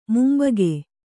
♪ mumbage